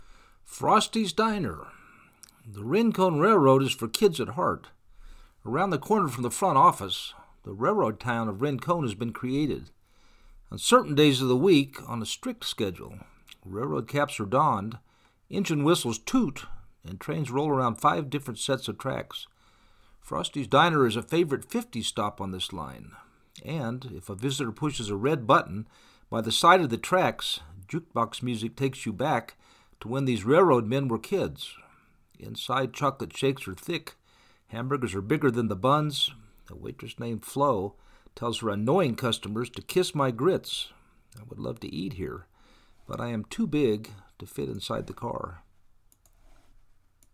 On certain days of the week, on a strict schedule, railroad caps are donned, engine whistles toot, and trains roll around five different sets of tracks. Frosty’s Diner is a favorite fifties stop on this line, and, if a visitor pushes a red button by the side of the tracks, jukebox music takes you back to when these railroad men were kids.